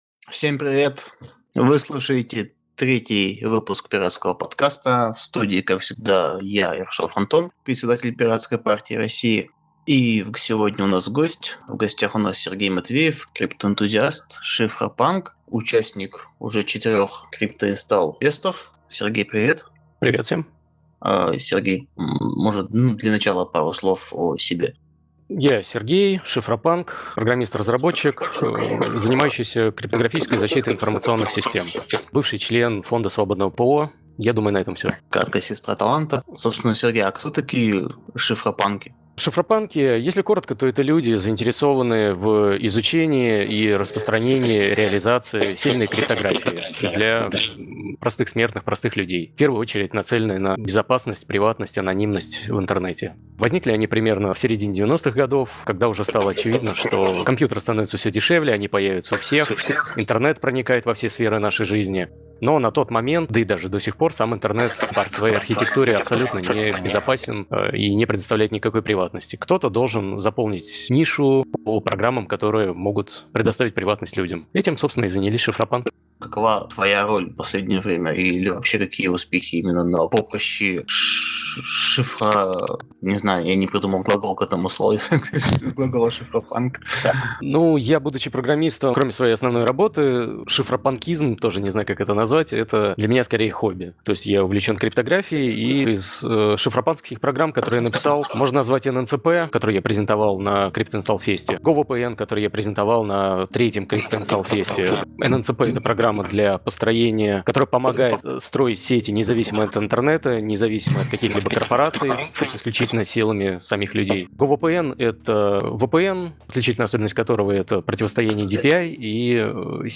Participated as a cypherpunk guest in Pirate Party of Russia’s podcast.